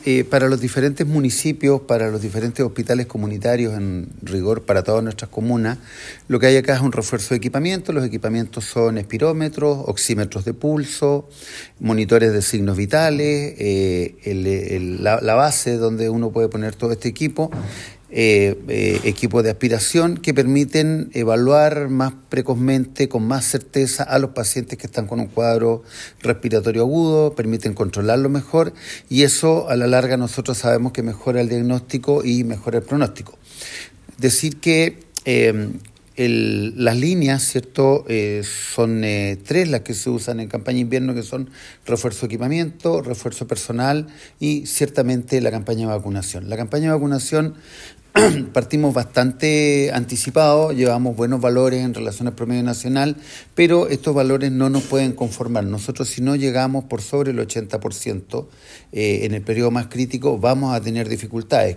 Por otra parte, el Director del Servicio de Salud de Osorno, Rodrigo Alarcón señaló que este refuerzo de equipamiento que incluye monitores de signos vitales, espirómetros, equipos de aspiración, entre otros serán destinados a los Centros de Salud Familiar de la Comuna de Osorno, San Pablo y San Juan de la Costa; además de los Hospitales de Puerto Octay, Füta Sruka Lawenche Kunko Mapu Mo, San Juan de la Costa; Hospital Pu Mullen de Quilacahuín y Hospital Base San José de Osorno.